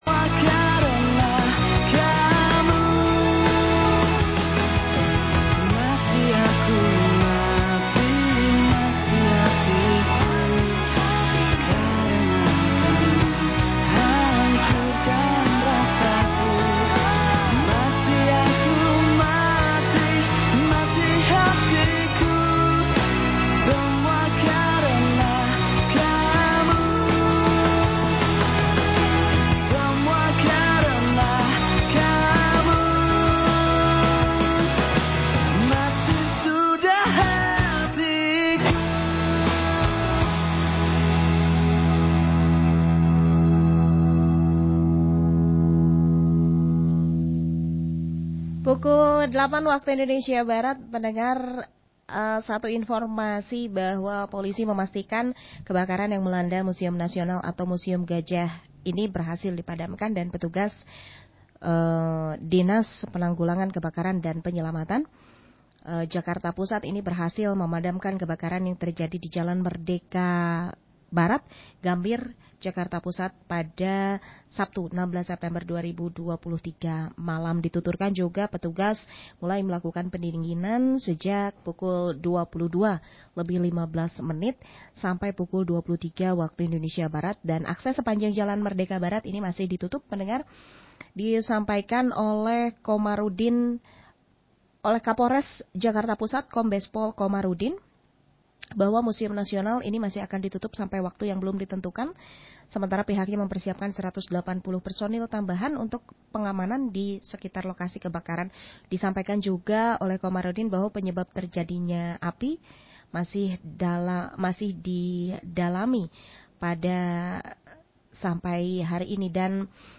Rekaman siaran